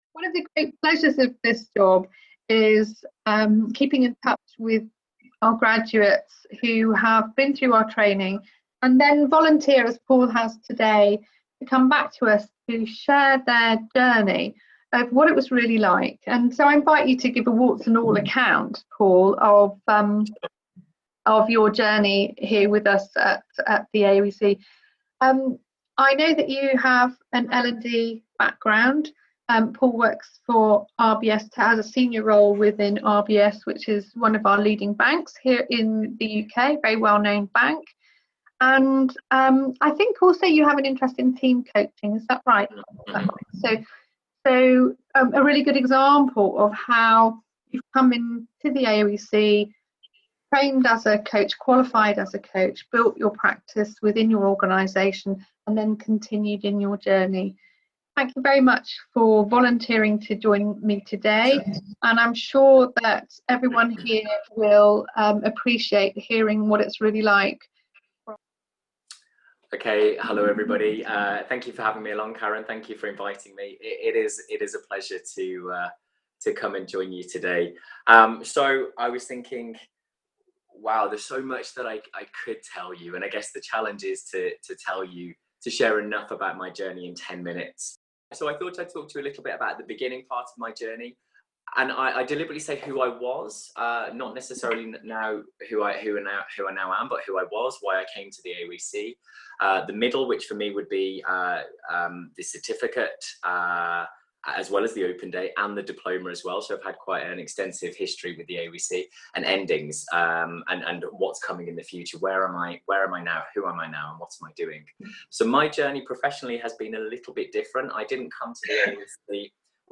Listen to an interview with one of our graduates